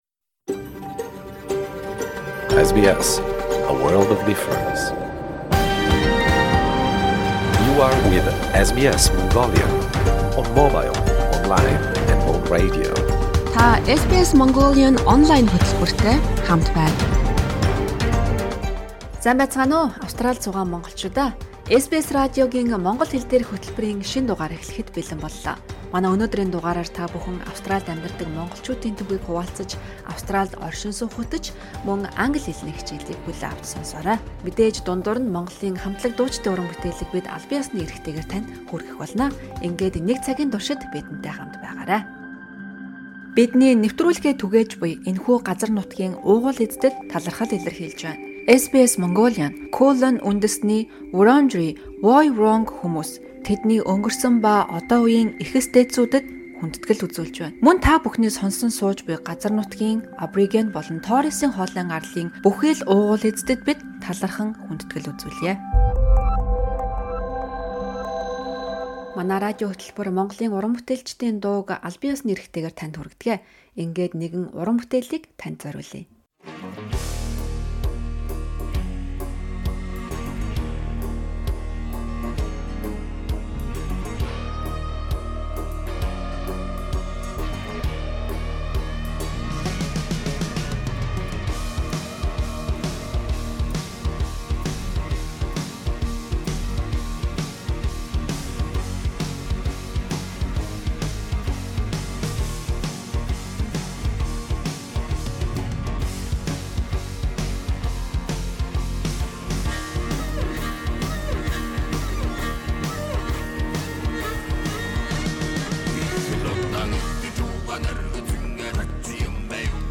Ярилцлага